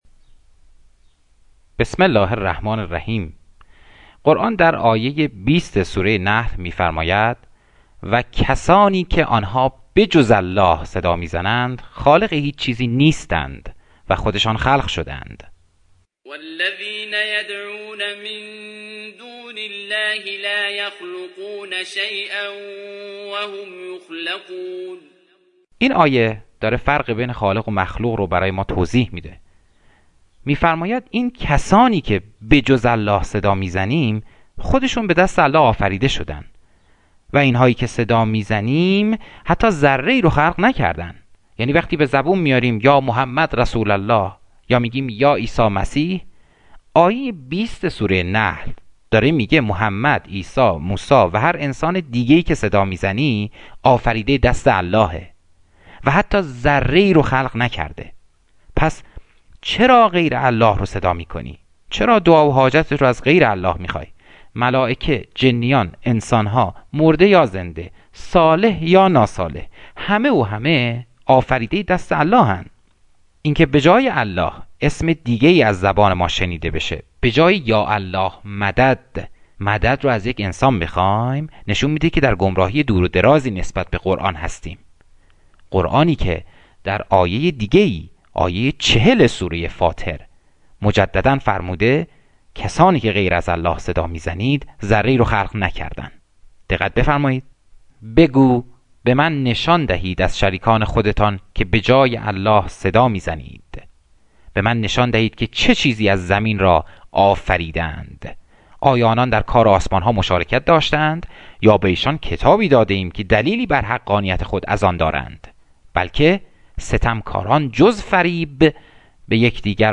پادکست، گفتگویی صوتی